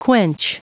Prononciation du mot quench en anglais (fichier audio)
Prononciation du mot : quench